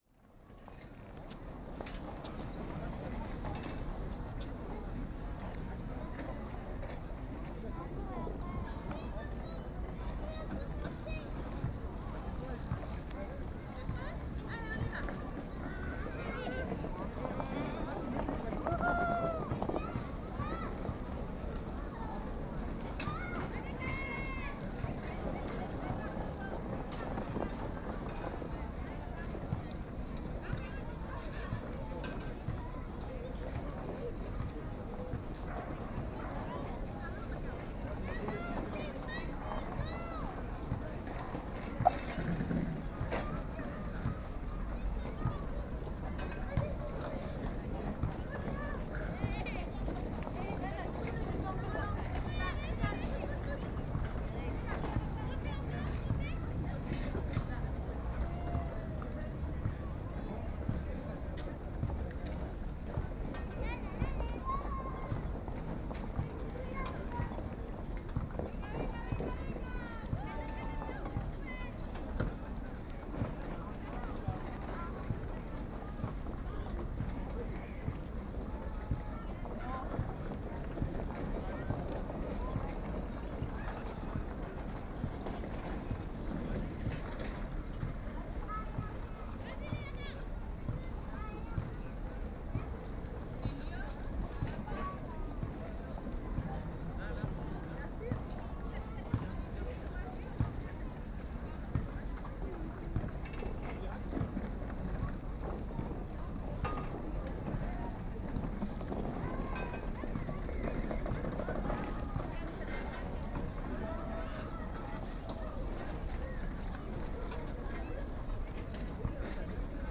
The pedal cars of the Champs de Mars, Paris.
• 1x Schoeps MSTC 64 U microphone (ORTF) + 2x Cinela Leo 20 + fur
• 1x Neumann KU 100 - Dummy Head (Binaural)
pedal_cars.mp3